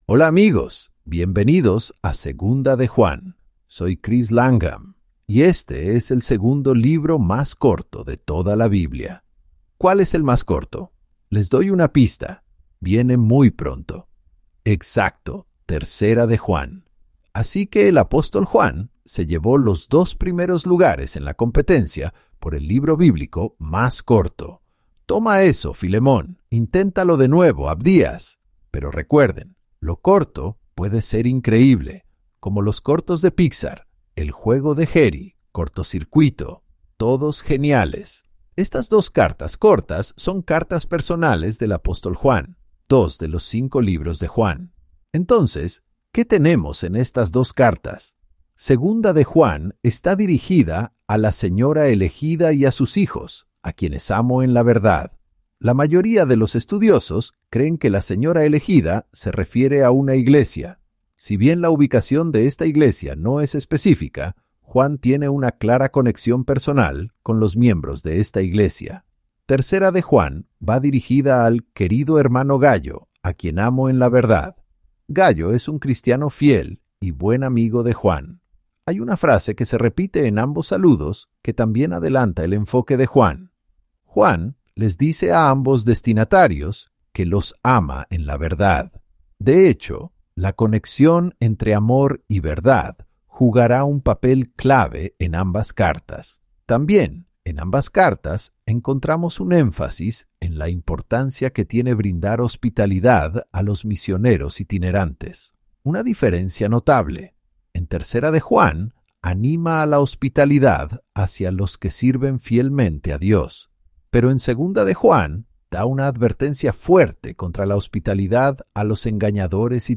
Divulgación Legal Para el beneficio de nuestros oyentes, TTW Bible Audio Guides (Guías de audio de “A Través de la Palabra”) utiliza tecnologías de IA para recrear las voces de los maestros en diferentes idiomas, con el apoyo de la supervisión humana y la garantía de calidad.